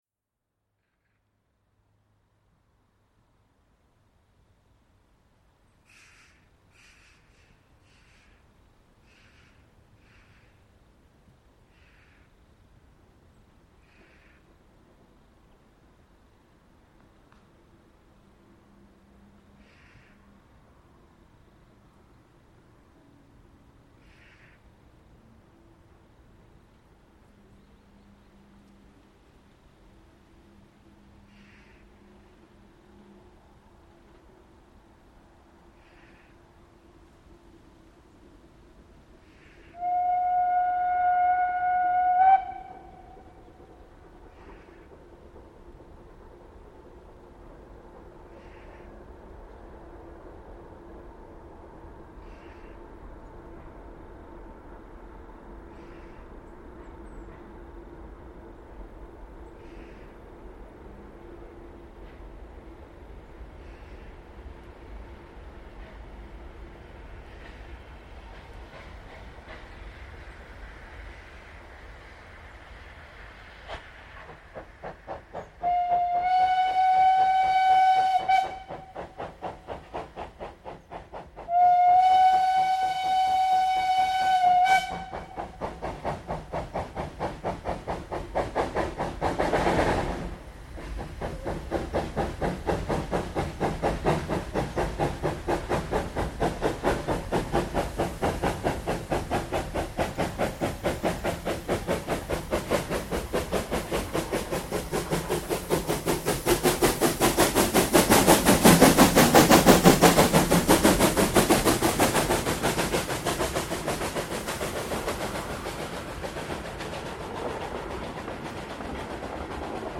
Was für eine laute und schöne Anfahrt für eine GtL4/4!:
98 886  mit Zug Mellrichstadt→Fladungen an der schon wiederholt aufgesuchten Aufnahmestelle: hinter Ausfahrt Mellrichstadt im Einschnitt bei Straße „An der Lehmgrube“ hinterm Bü der Siedlungsstraße Malbachweg, und zwar schon im leichten Nieselregen, um 11:16h am 03.08.2025.   Hier anhören: